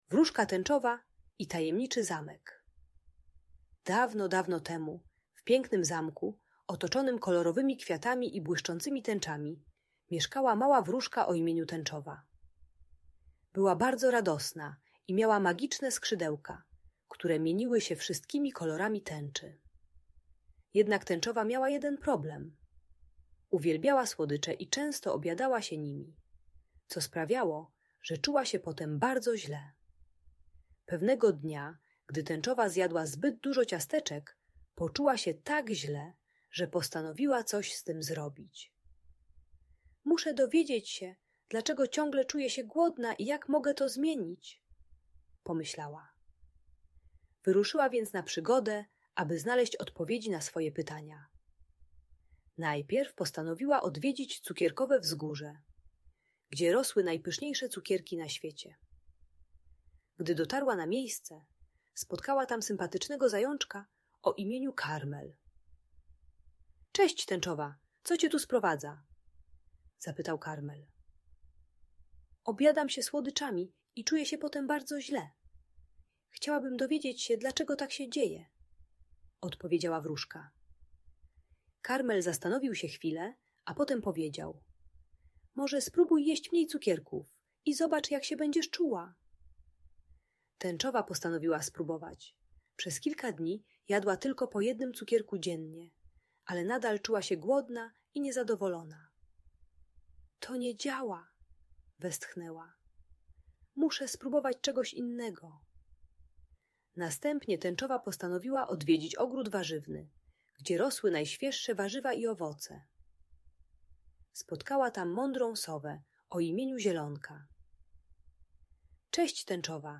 Wróżka Tęczowa i Tajemniczy Zamek - Audiobajka